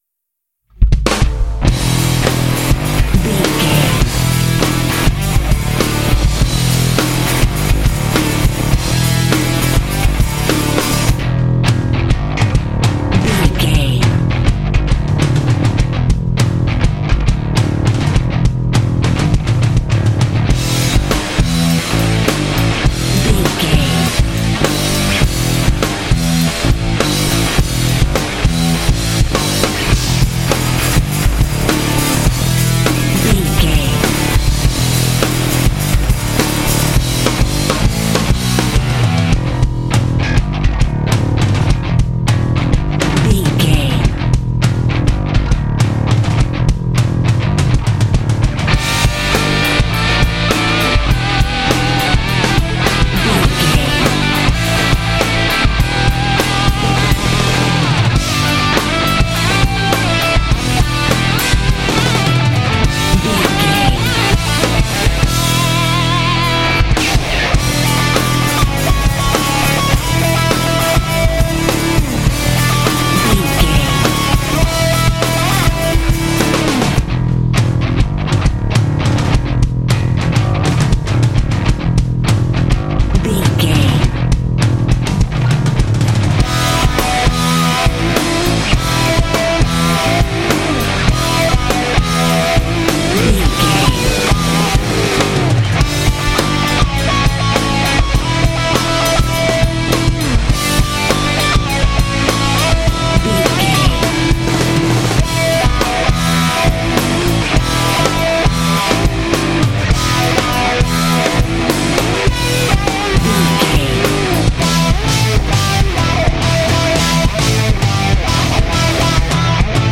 Aeolian/Minor
powerful
energetic
heavy
bass guitar
electric guitar
drums
heavy metal
classic rock
symphonic rock